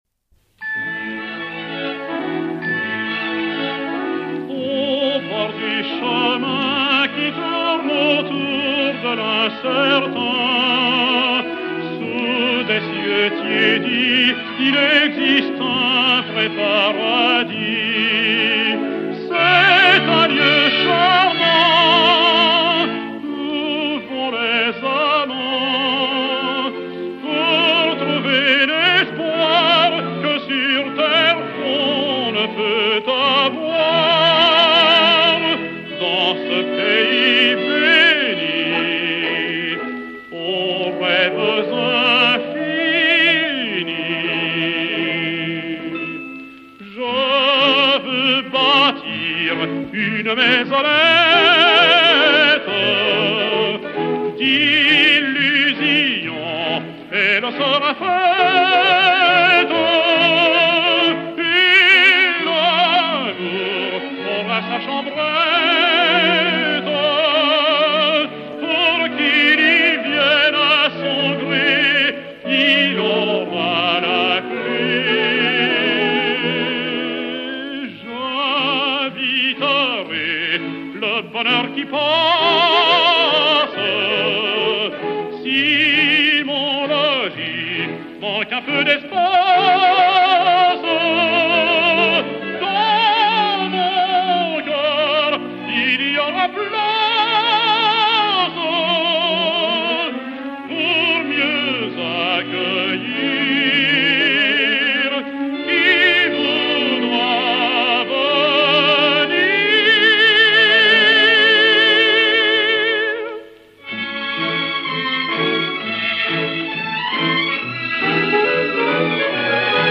Mélodie
ténor de l'Opéra